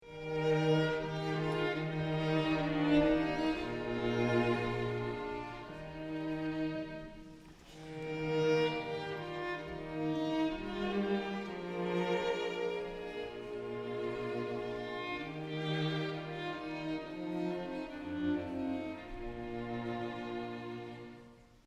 Instrumentalensemble